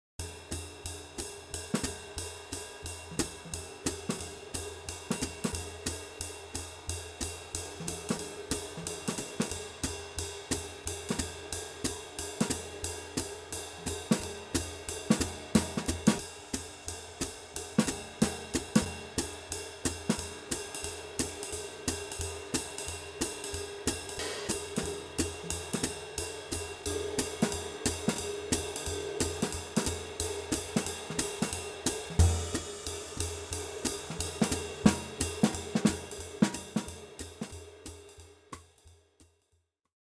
爵士架子鼓采样包
Brush Kit WAV Project 分轨文件